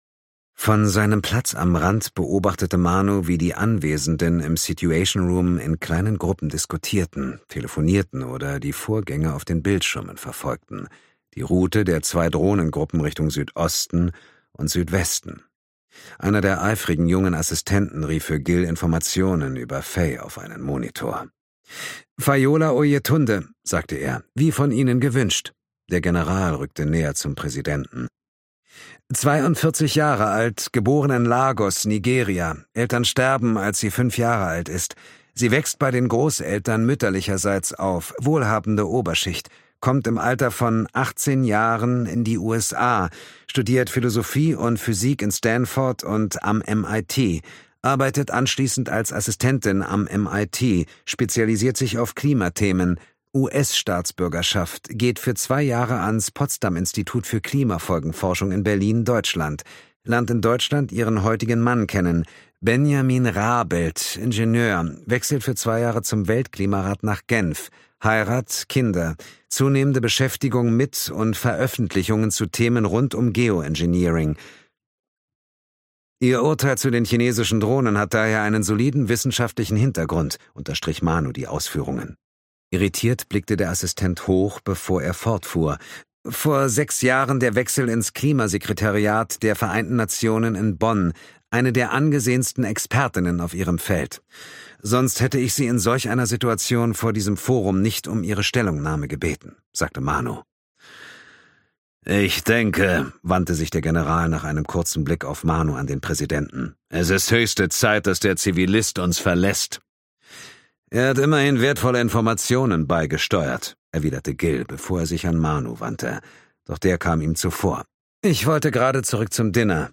Steffen Groth , Simon Jäger , Dietmar Wunder (Sprecher)
Ungekürzte Lesung